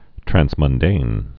(trănsmŭn-dān, trănz-, trăns-mŭndān, trănz-)